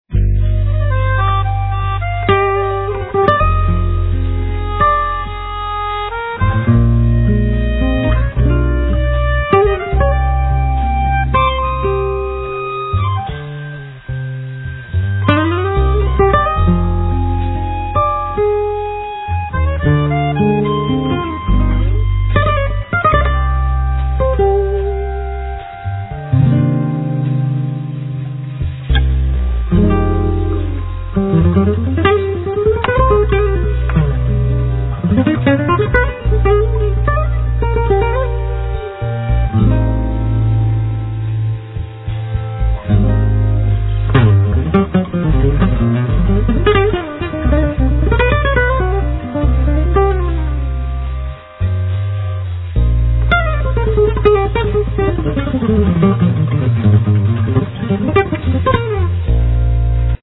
Carlos do Carmo   Guitar
Acoustic bass
Accordion
Drums